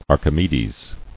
[Ar·chi·me·des]